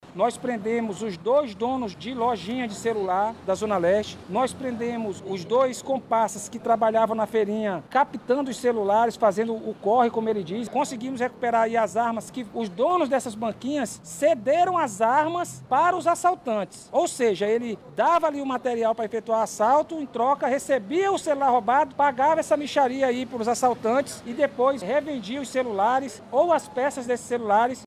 O comandante detalha como funcionava o esquema de roubo de aparelhos celulares.
SONORA-3-PRISAO-QUADRILHA-ASSALTO-.mp3